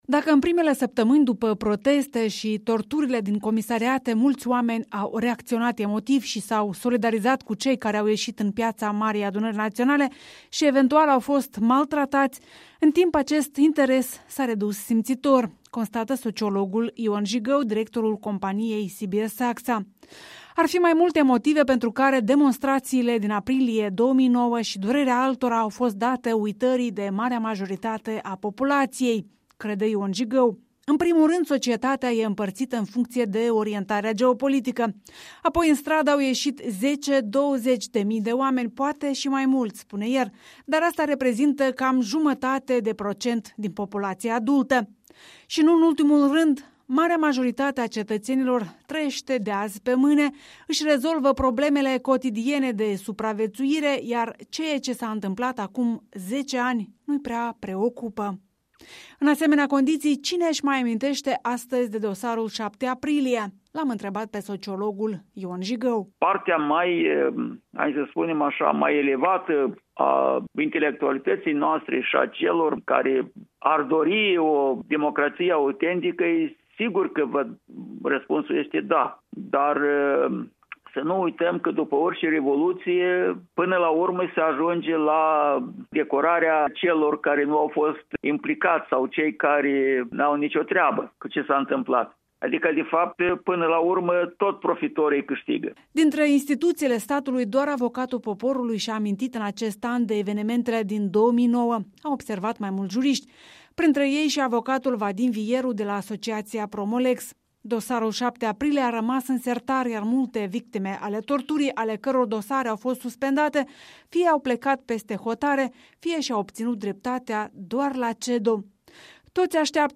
Sociologul